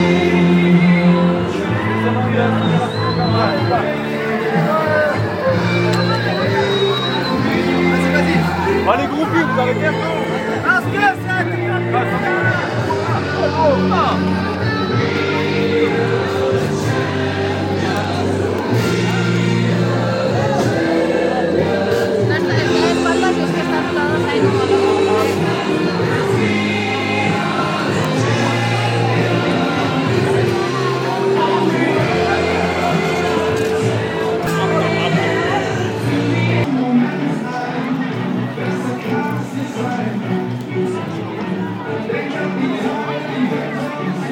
Gravació feta diumenge 26 d'Octubre al Port Aventura en el decurs del Rally Catalunya 2014. Es recull el moment de l'arribada de Sébastien Ogier i Julien Ingrassa a boxes.